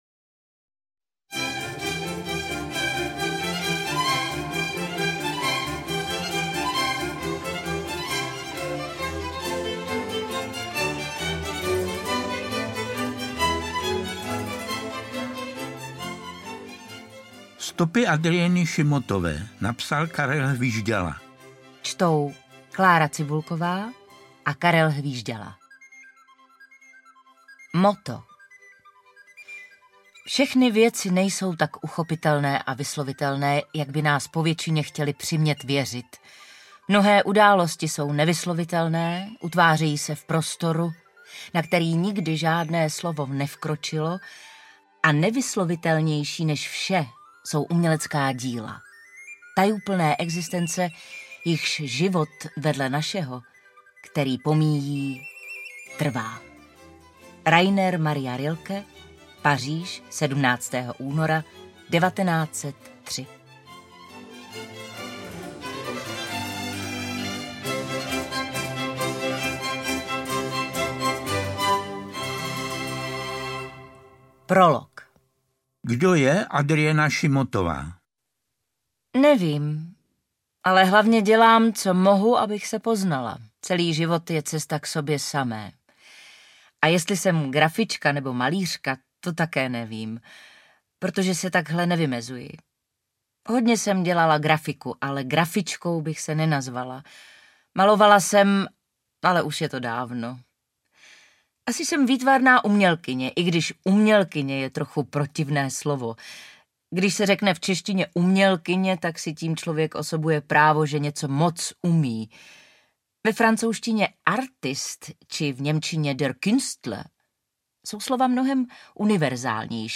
Interpreti:  Klára Cibulková, Karel Hvížďala